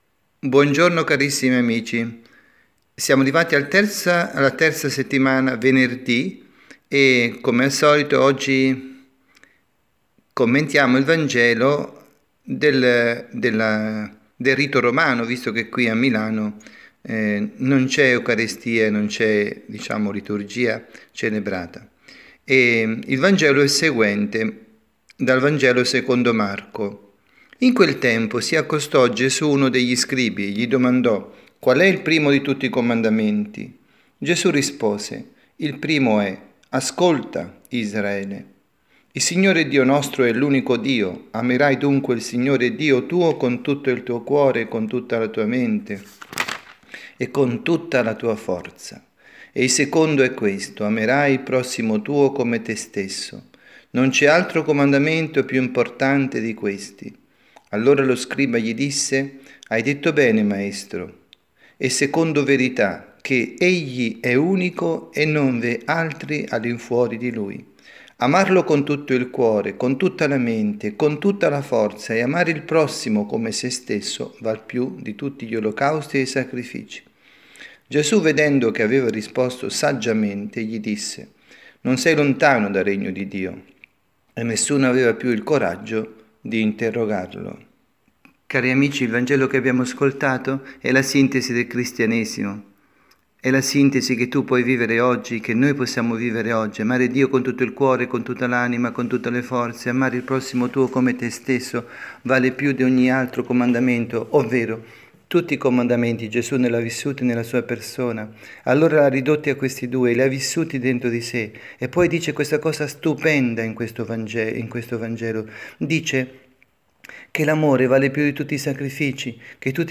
Omelia
dalla Casa di Riposo Santa Marta, Milano